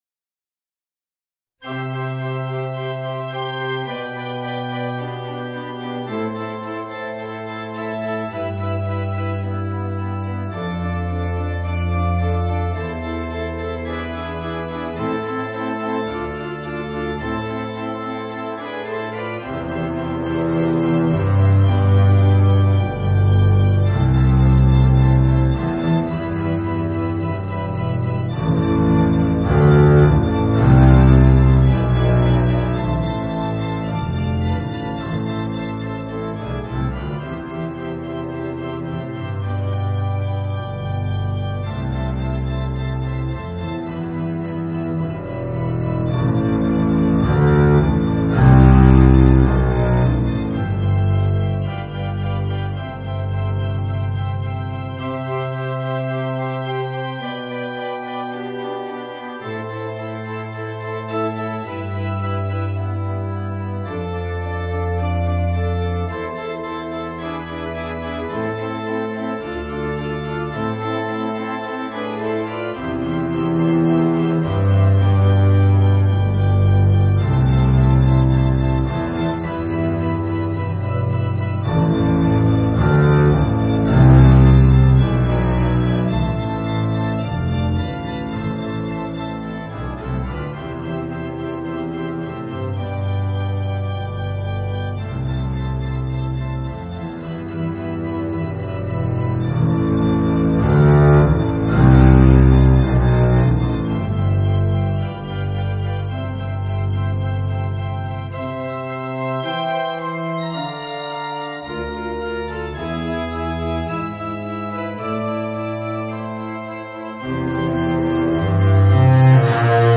Voicing: Contrabass and Organ